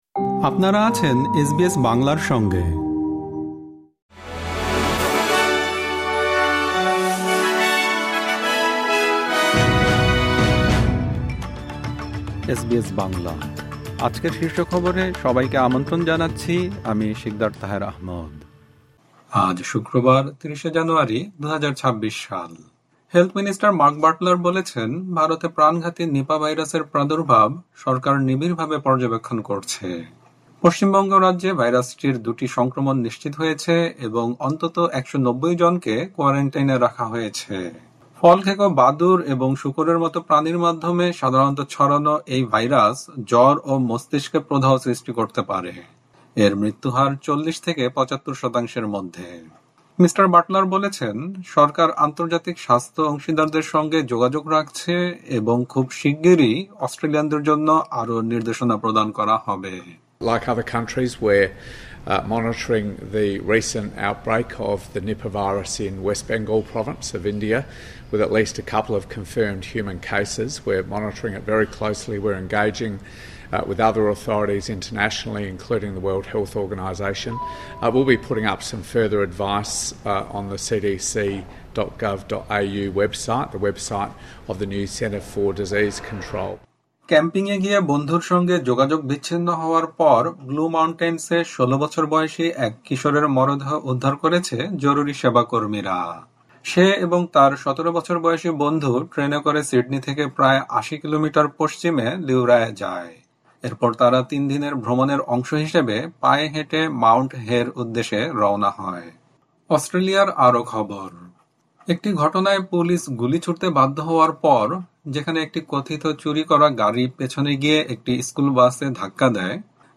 এসবিএস বাংলা শীর্ষ খবর: ভারতে নিপাহ ভাইরাসের প্রাদুর্ভাব নিবিড়ভাবে পর্যবেক্ষণ করছে অস্ট্রেলিয়া সরকার